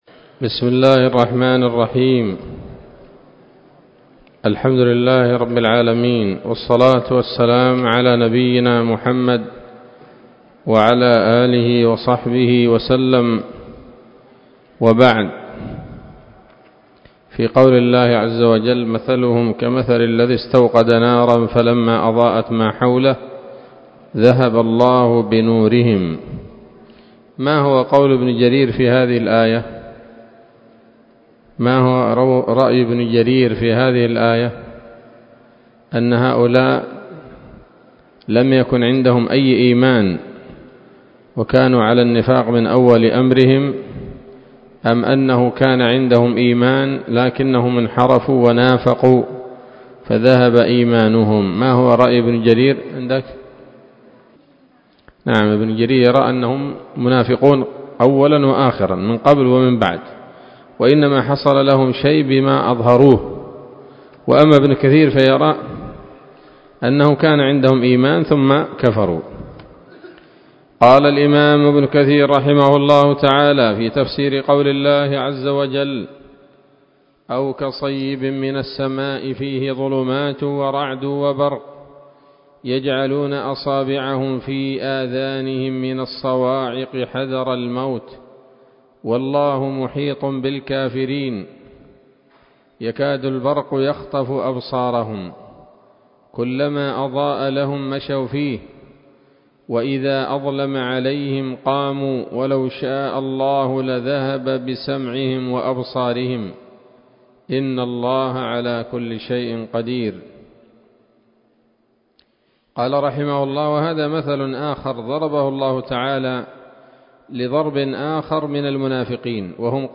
الدرس الحادي والعشرون من سورة البقرة من تفسير ابن كثير رحمه الله تعالى